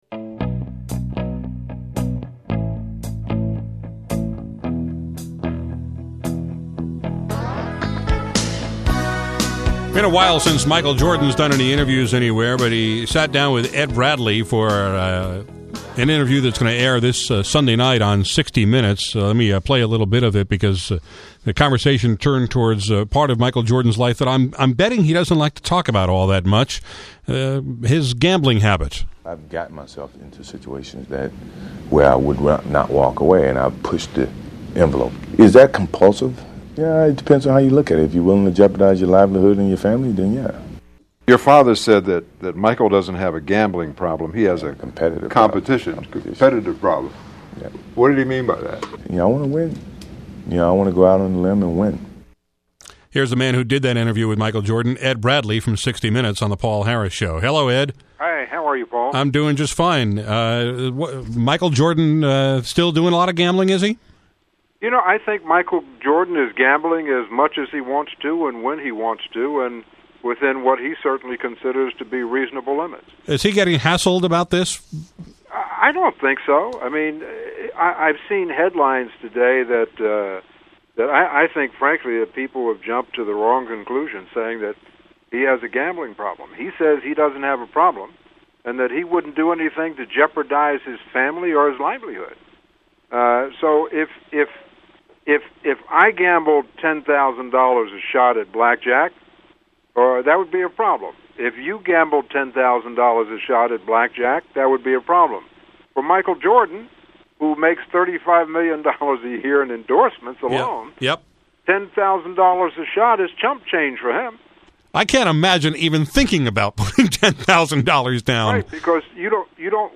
Ed Bradley of “60 Minutes” called into my show this afternoon to talk about a piece he’s doing this Sunday on Michael Jordan. We talked about Jordan’s gambling, his competitiveness, and what it’s like to go to a basketball fantasy camp and have Jordan trash-talk you.